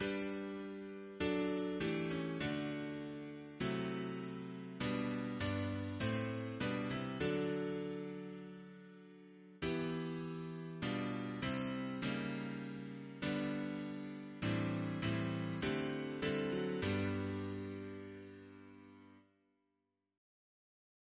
Double chant in G Composer: Chris Biemesderfer (b.1958)